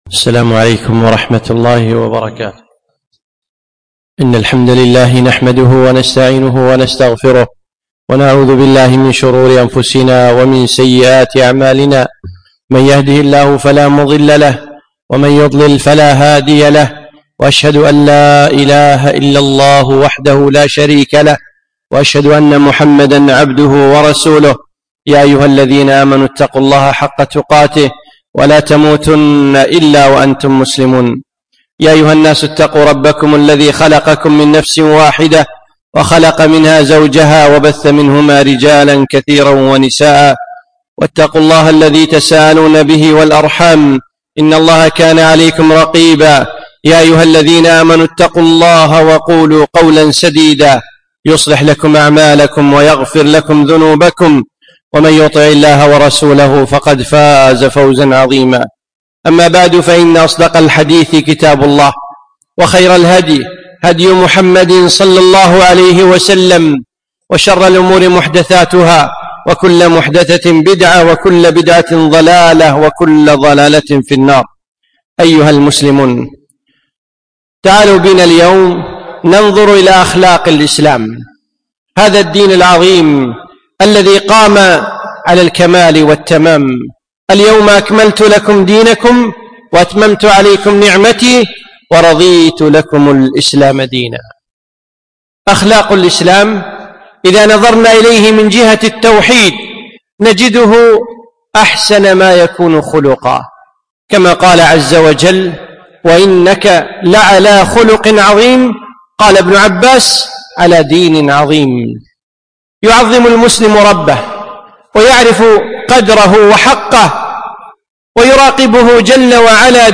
خطبة الجمعة (( خطورة الاستهزاء بالدِّين ))